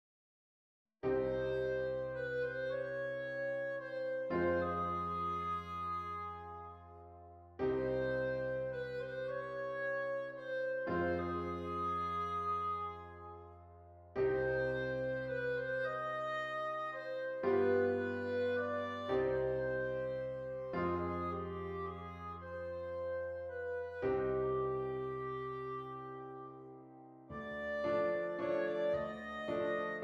Clarinet Solo with Piano Accompaniment
Does Not Contain Lyrics
C Minor
Slow Waltz